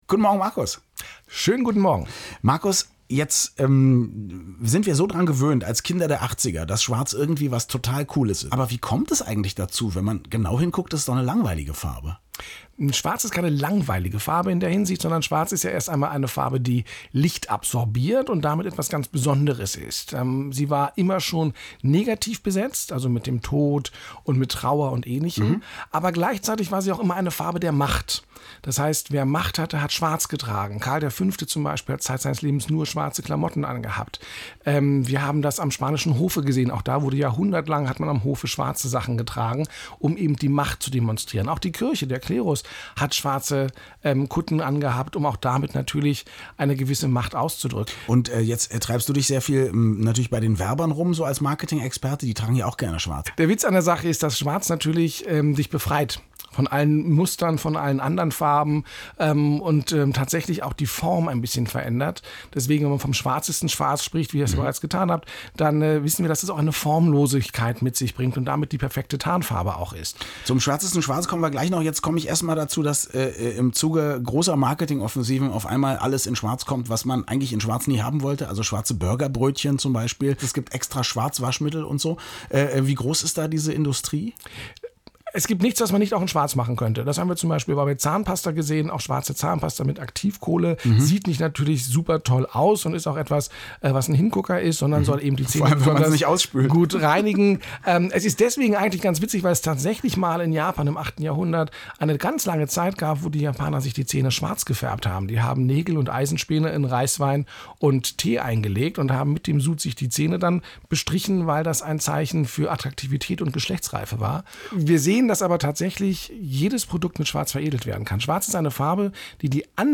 „Schwarz“ ist das Thema der heutigen Sendung von „zweiaufeins“ auf radioeins und natürlich habe ich extra schwarzen Senf angerührt, den ich dazugeben kann: